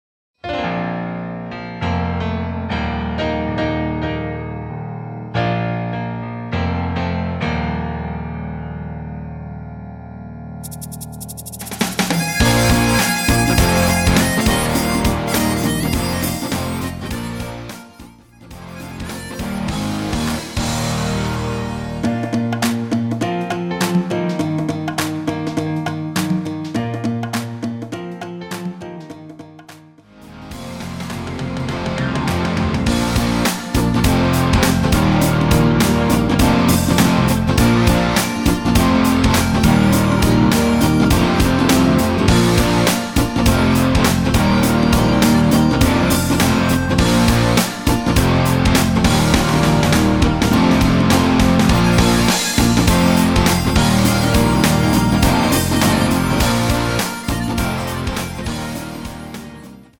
Bb
앞부분30초, 뒷부분30초씩 편집해서 올려 드리고 있습니다.
곡명 옆 (-1)은 반음 내림, (+1)은 반음 올림 입니다.